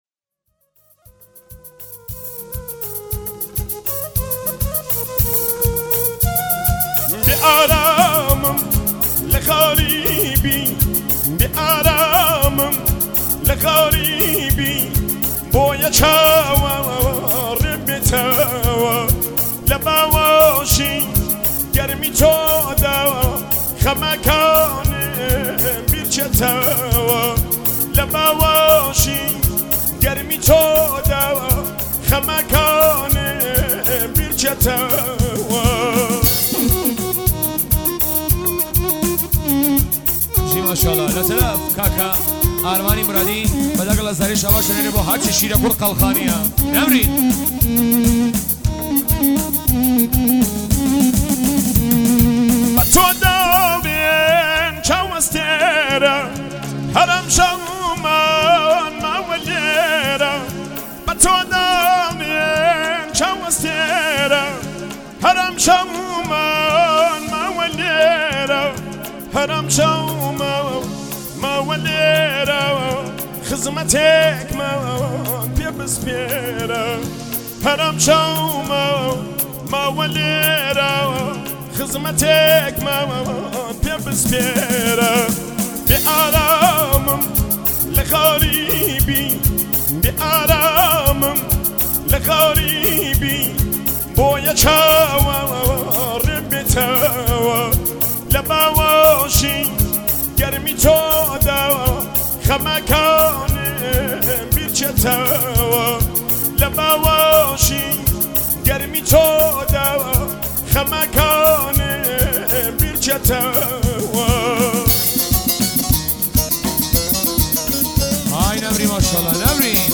کردی